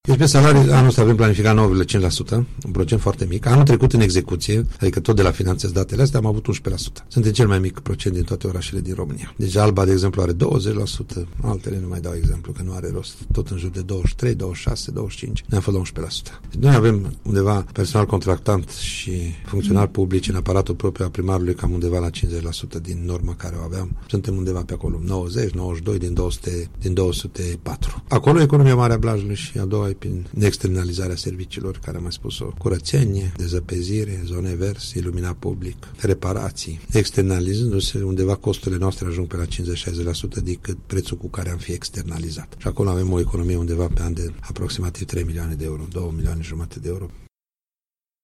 Primarul Blajului, Gheorghe Valentin Rotar, a vorbit la Unirea FM despre cheltuielile salariale mici de anul trecut.